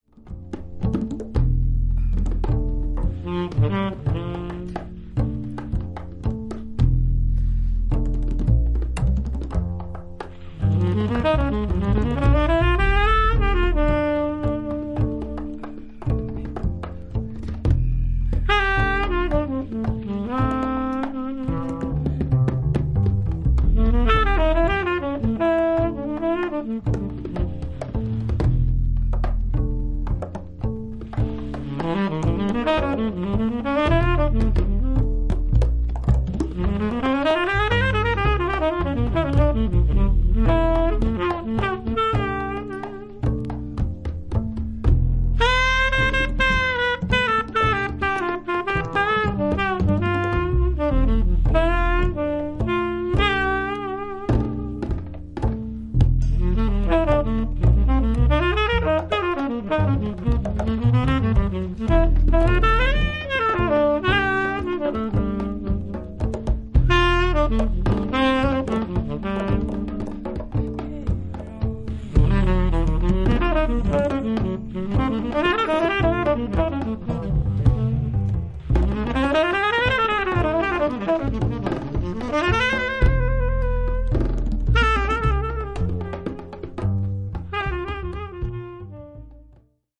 アンティル諸島出身のベーシスト/シンガー